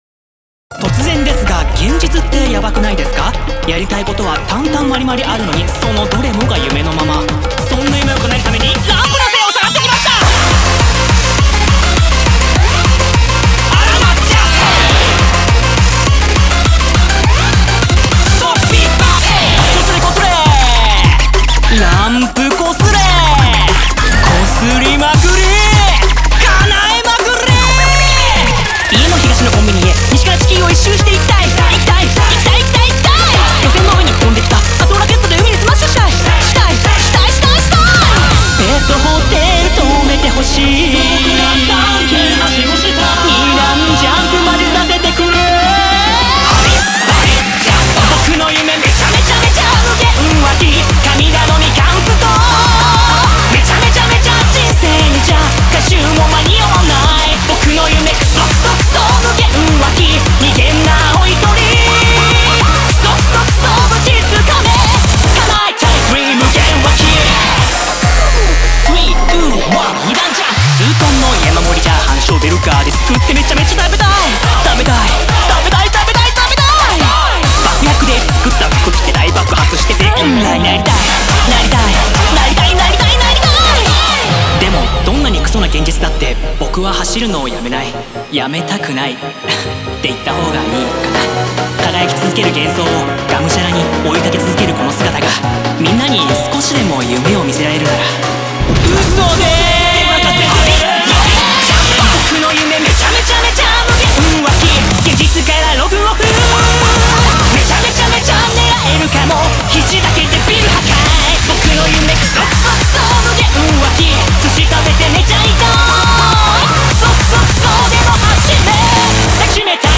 BPM80-205
Audio QualityPerfect (High Quality)